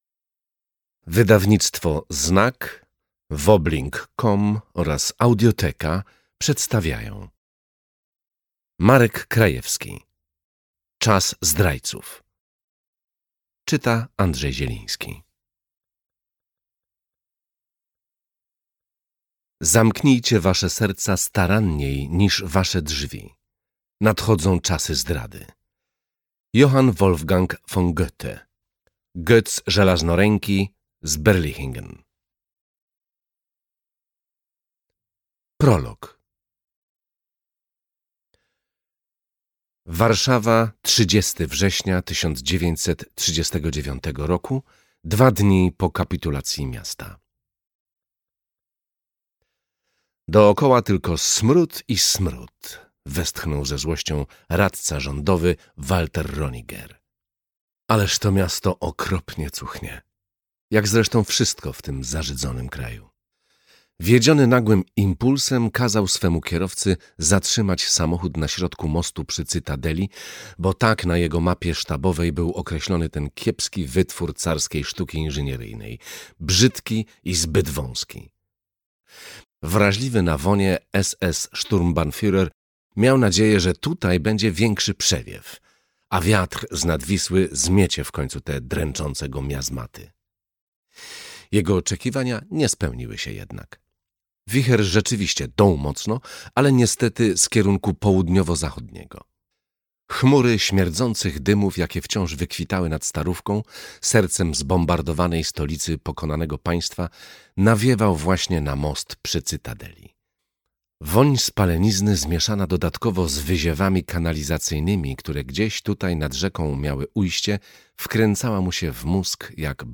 Czas zdrajców - Marek Krajewski - audiobook + książka